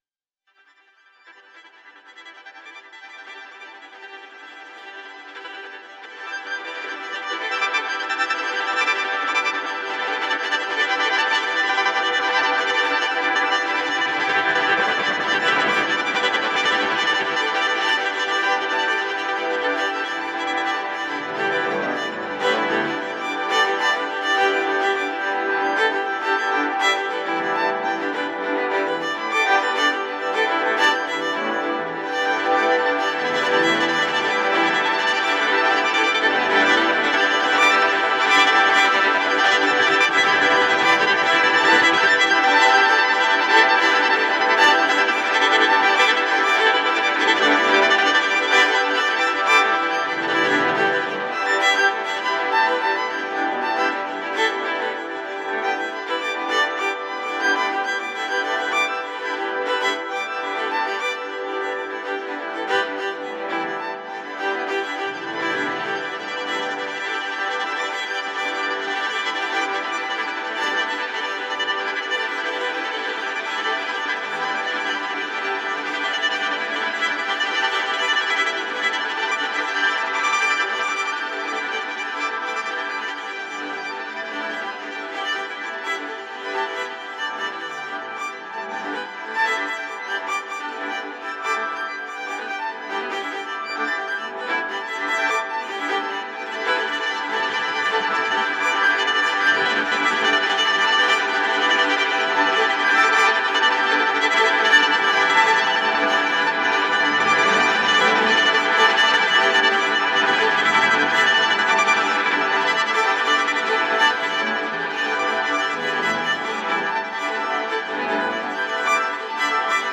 本作もまた、そうしたイメージを持ちつつ、更に複雑な多重構造により、宇宙の構成を表したような印象があります。
ギター愛好家の方々にはもちろん、現代音楽、先端的テクノ、実験音楽をお好きな方々にもお薦めのアルバムです。